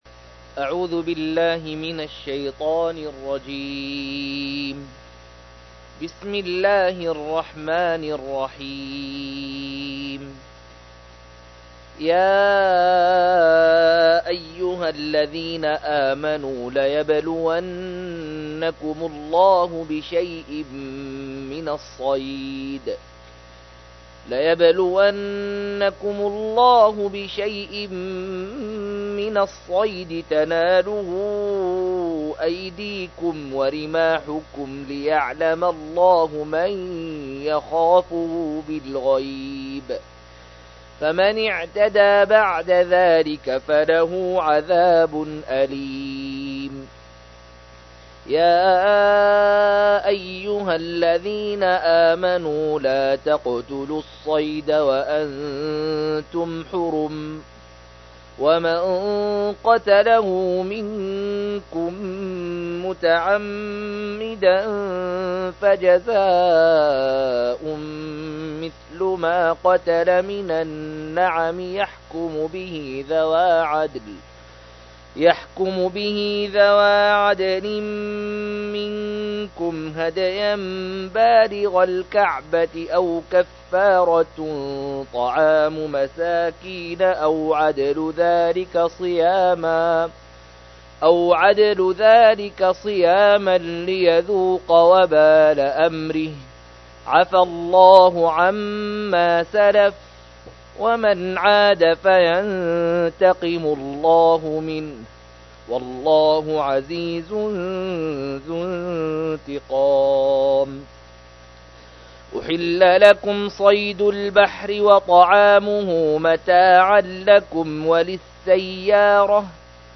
121- عمدة التفسير عن الحافظ ابن كثير رحمه الله للعلامة أحمد شاكر رحمه الله – قراءة وتعليق –